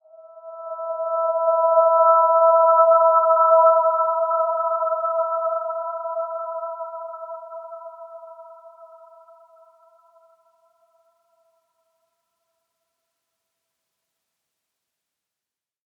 Dreamy-Fifths-E5-mf.wav